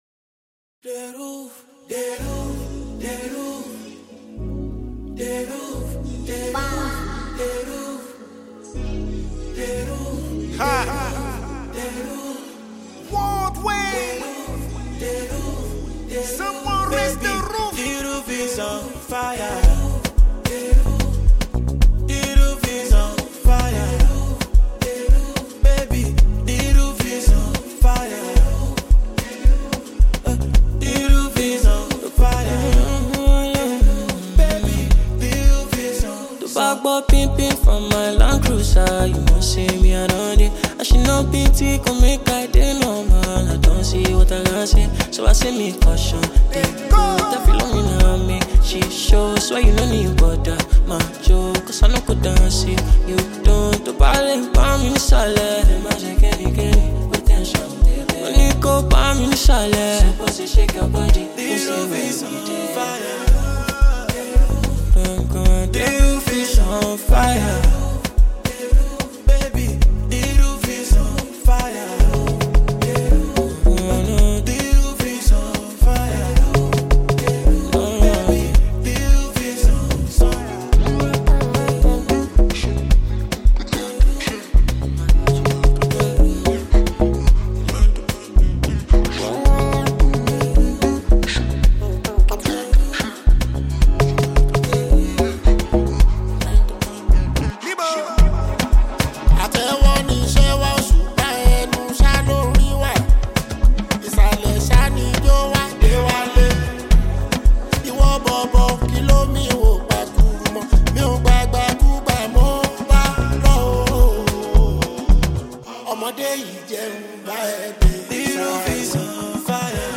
scorching song